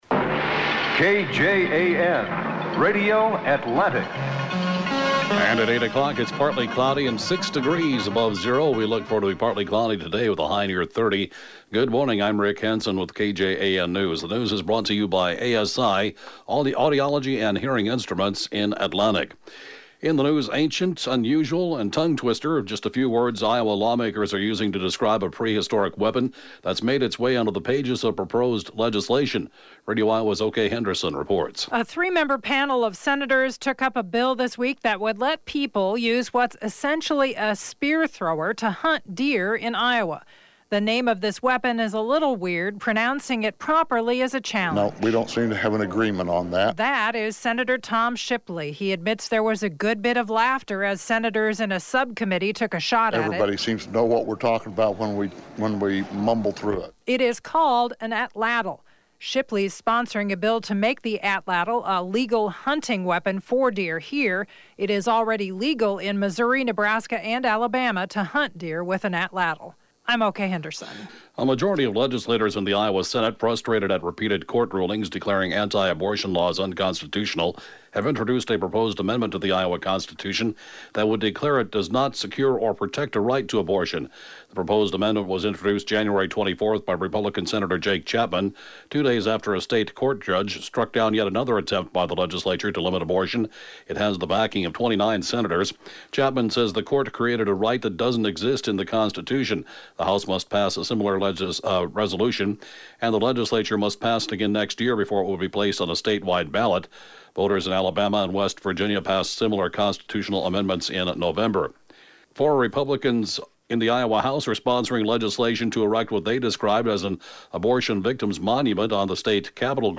(Podcast) KJAN 8-a.m. News, 2/1/2019